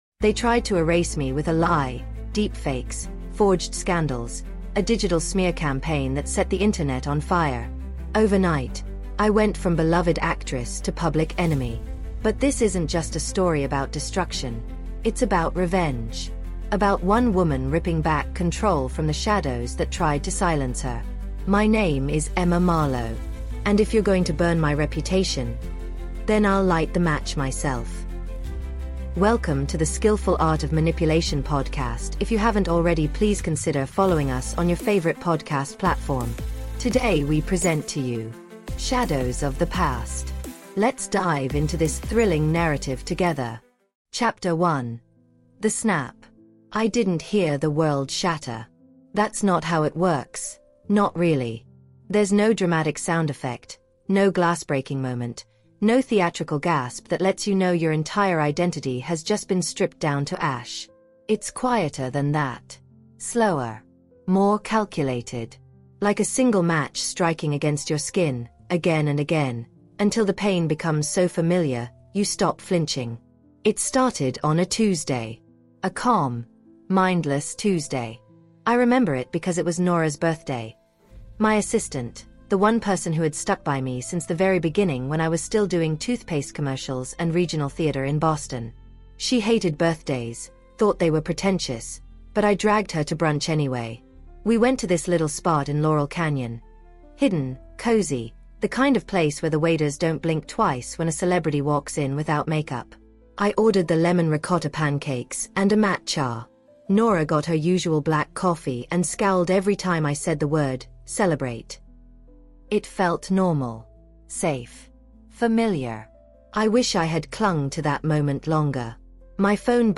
Shadows of the Past is a gripping psychological thriller told in raw, emotionally charged first-person narration.